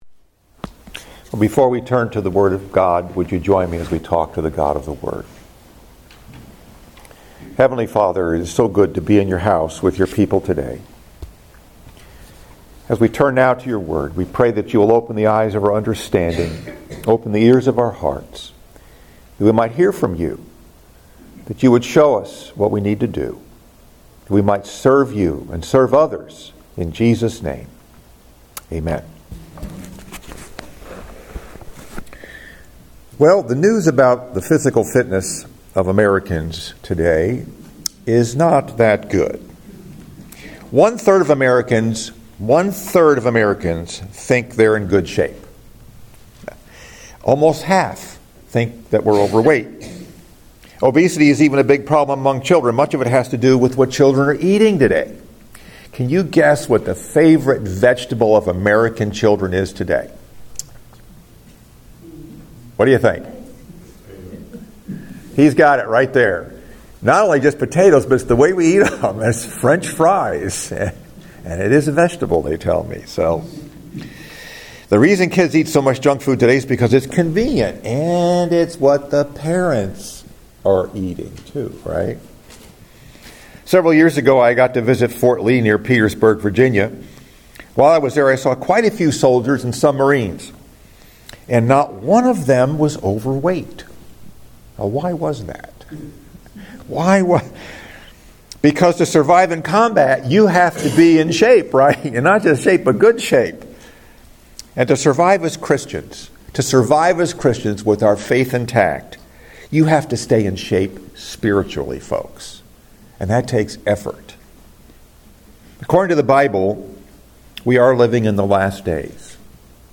Message